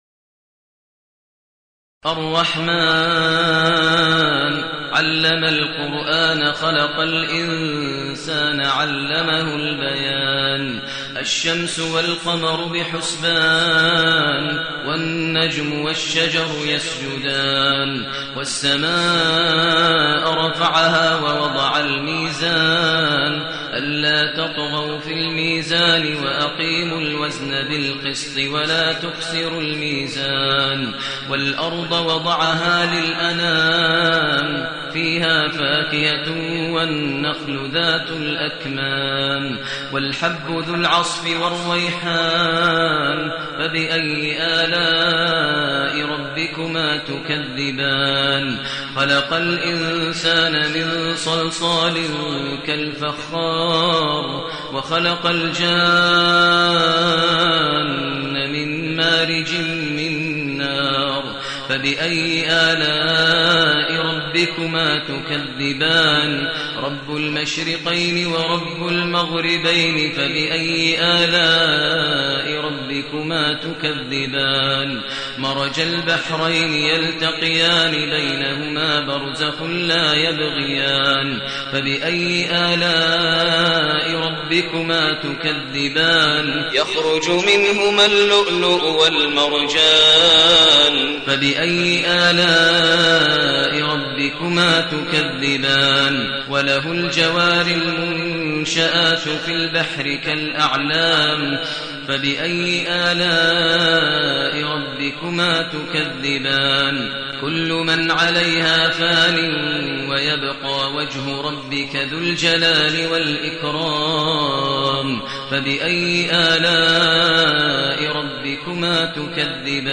المكان: المسجد الحرام الشيخ: فضيلة الشيخ ماهر المعيقلي فضيلة الشيخ ماهر المعيقلي الرحمن